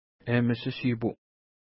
Pronunciation: emiʃə-ʃi:pu:
Pronunciation